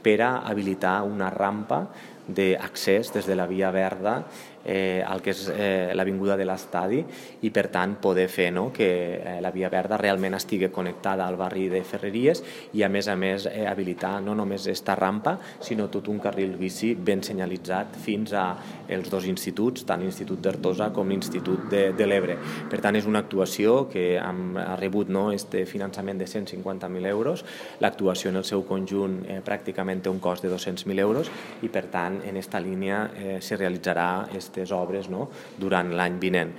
L’alcalde de Tortosa, Jordi Jordan, destaca que amb aquest projecte es treballa per  ampliar la xarxa de carrils bici que actualment està fragmentada i connectar-la amb l’objectiu d’aconseguir una mobilitat més segura i sostenible…